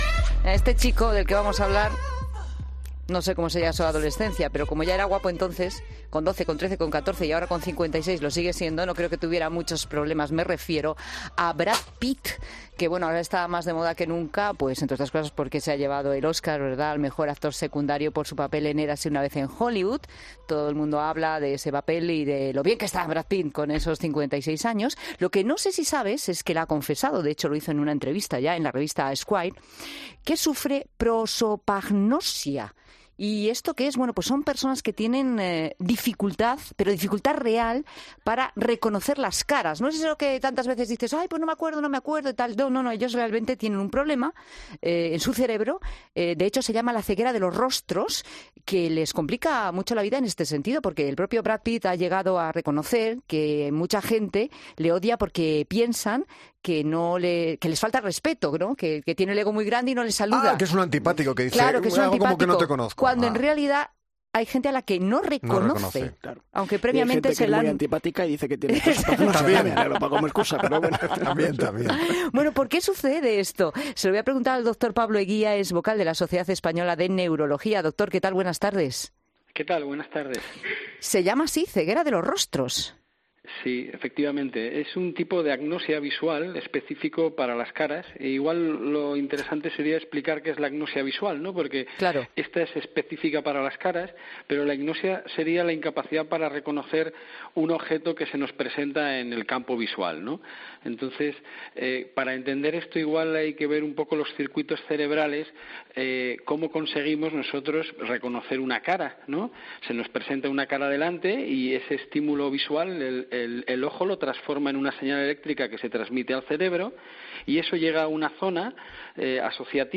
El neurólogo